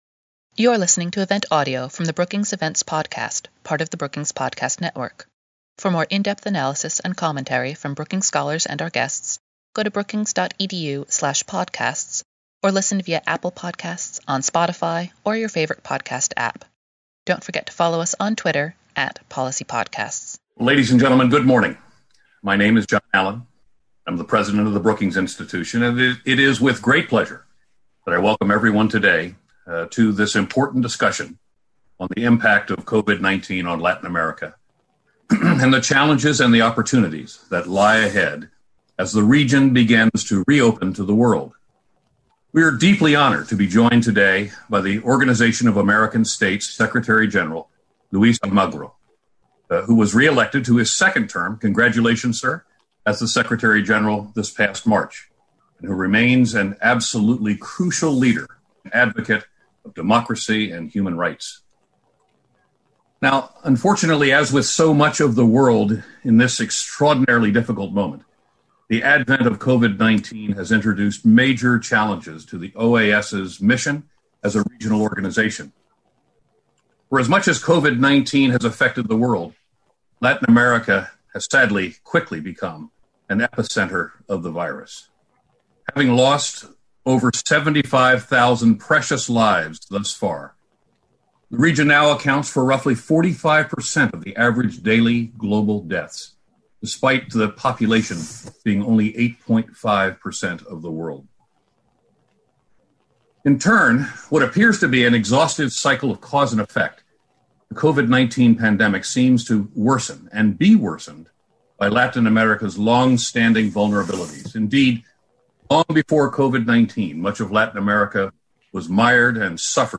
On June 22, the Global Economy and Development program at Brookings hosted a virtual conversation with the Secretary-General of the Organization of American States (OAS), H.E. Luis Almagro, to discuss the challenges and opportunities facing Latin America in the face of COVID-19.
On June 22, the Global Economy and Development program at Brookings hosted a virtual conversation with Secretary General of the Organization of American States (OAS) H.E. Luis Almagro to discuss the challenges and opportunities facing Latin America as well as Mr. Almagro’s vision and priorities for his second term as OAS secretary general.